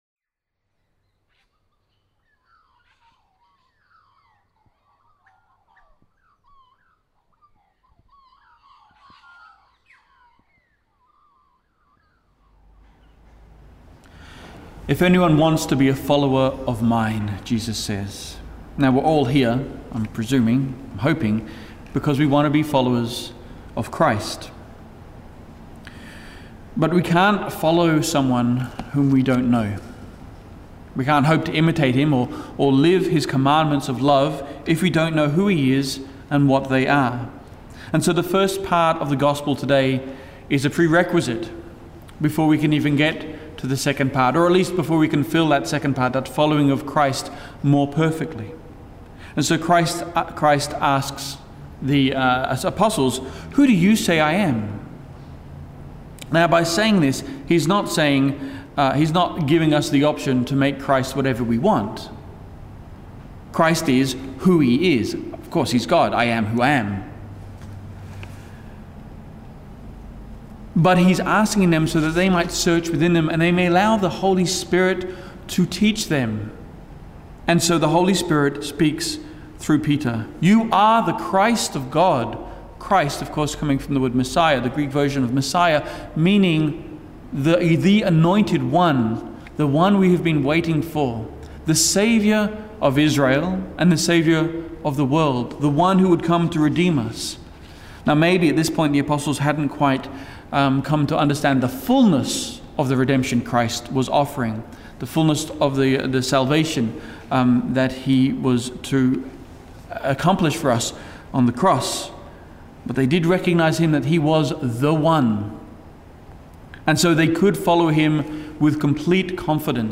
Homily
This was during a second Mass, later in the morning, for the participants of our Third Order (MIM) Retreat Weekend.